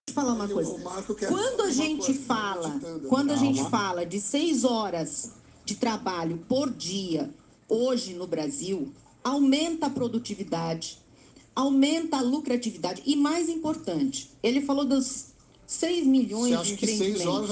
A disparidade mais significativa é na pronúncia da letra “R”: a manifestante golpista prolonga mais o som da letra do que a ex-candidata (ouça a comparação abaixo).